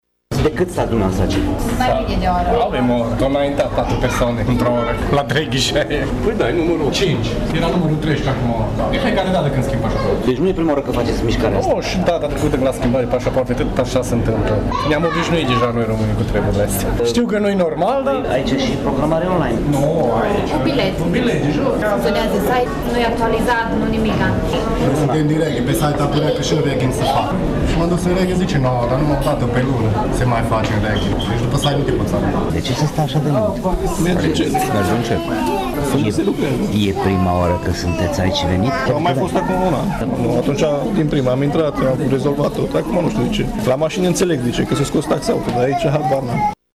Alți părinți spun că aceste întârzieri se petrec în fiecare an și că e o situație întâlnită în toată țara: